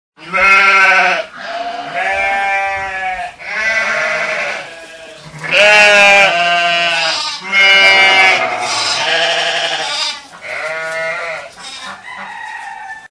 Звуки баранов, овец
Бараны и овцы в загоне